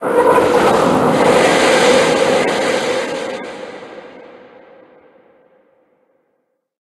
Cri de Melmetal Gigamax dans Pokémon HOME.